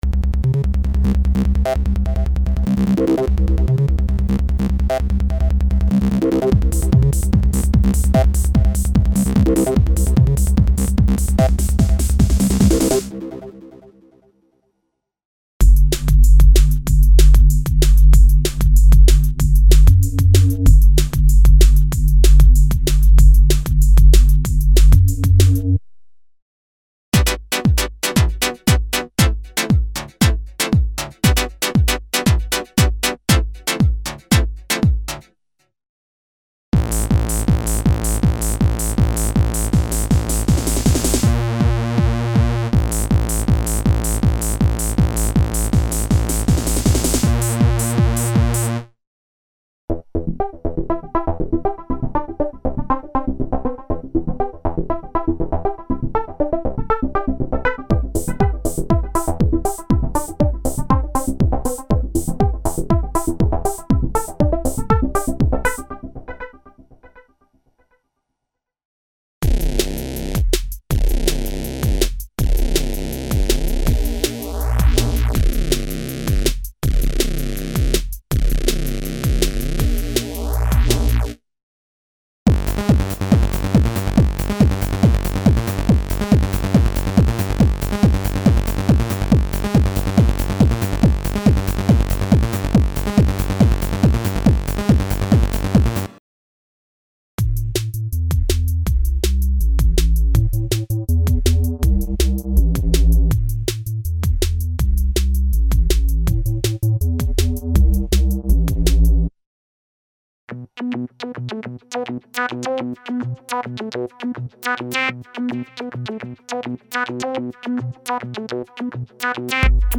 Original collection of modern stacks, synth chords, filtered tone intervals and sound banks specially designed for Jungle, Trance and Techno music styles.
Info: All original K:Works sound programs use internal Kurzweil K2600 ROM samples exclusively, there are no external samples used.
K-Works - Modern Synth Volume 4 - EX (Kurzweil K2xxx).mp3